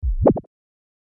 ムニュ 06
/ F｜演出・アニメ・心理 / F-18 ｜Move コミカルな動き / ムニュ2
プヨ